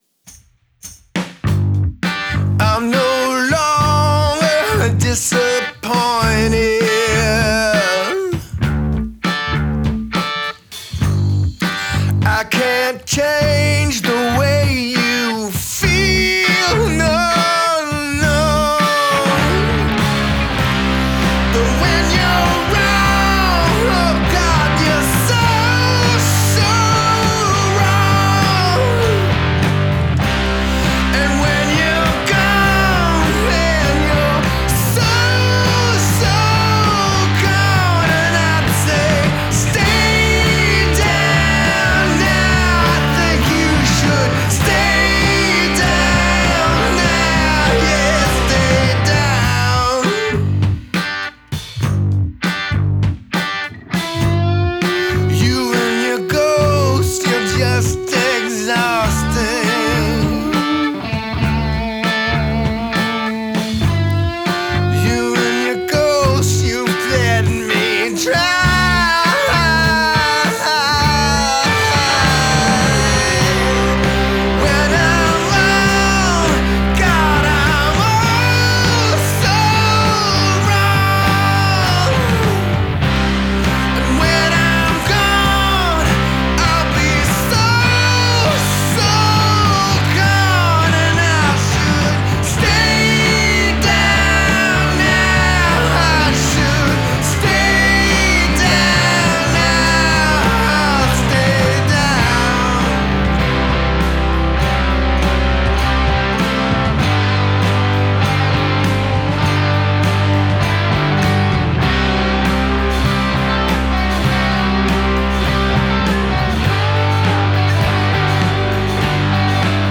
It’s punk rock. It’s loud.